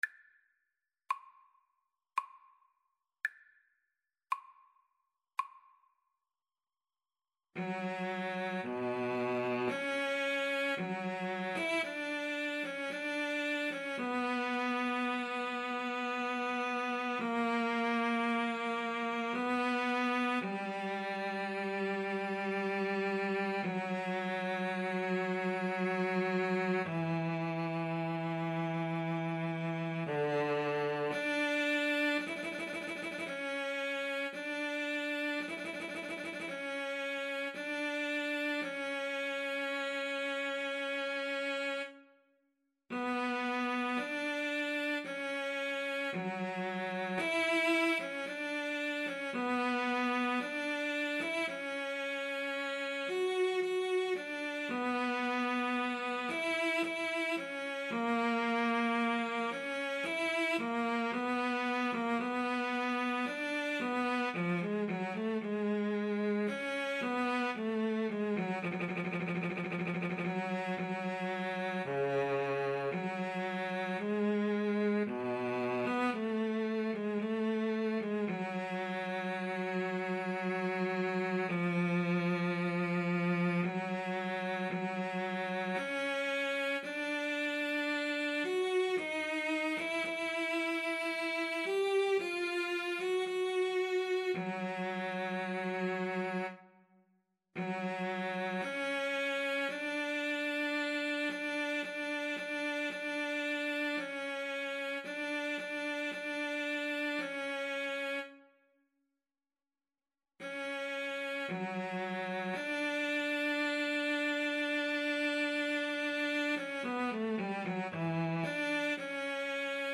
= 56 Largo
Classical (View more Classical Cello Duet Music)